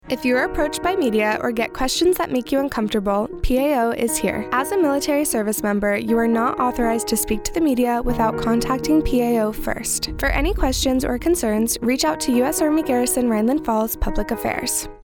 A 15-second radio spot reminding you to talk to PAO if you are approached by the media that will air on AFN Kaiserslautern from Jan. 9, 2026, to Jan. 9, 2027.